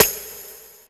PharrellSnare.wav